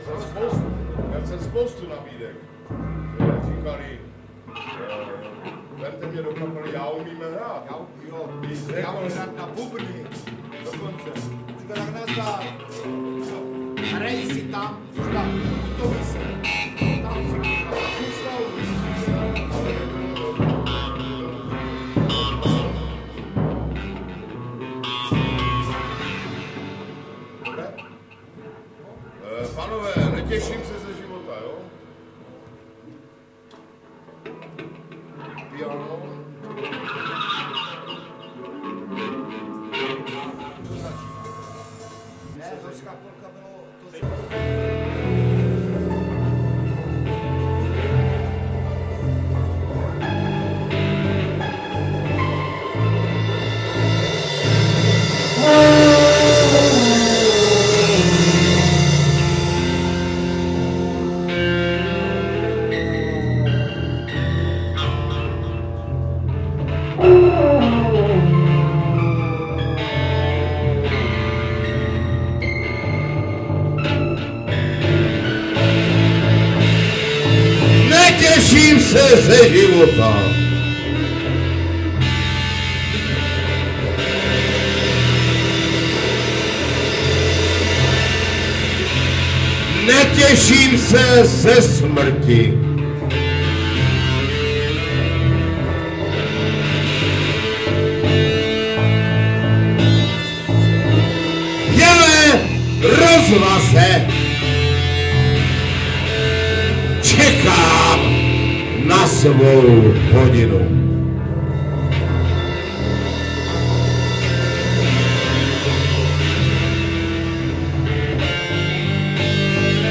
ponurá-devátá, mp3 ke stažení ponurá (zkušebna)
zpěv, varhany, lesní roh
bicí
kytara
sax